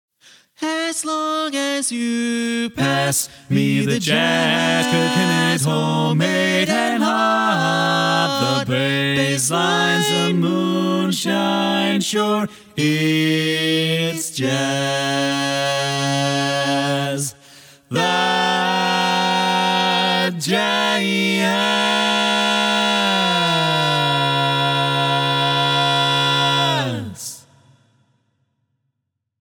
Key written in: D♭ Major
Type: Barbershop